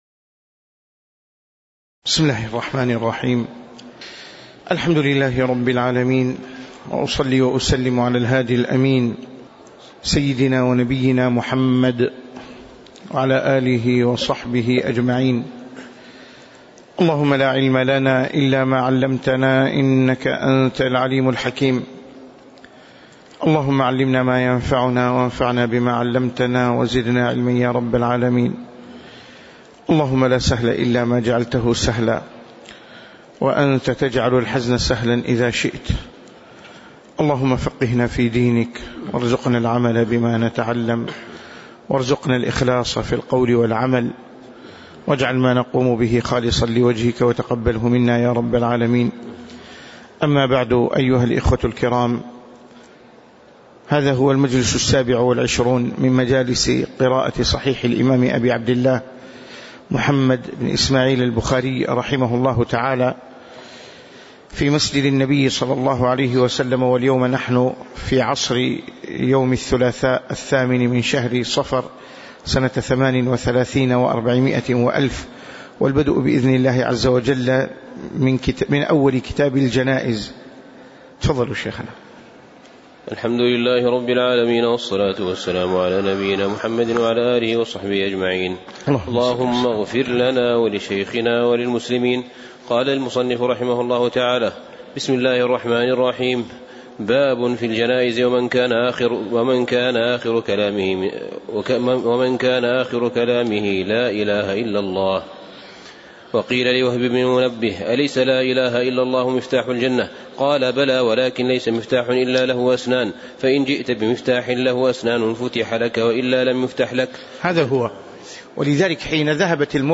تاريخ النشر ٨ صفر ١٤٣٨ هـ المكان: المسجد النبوي الشيخ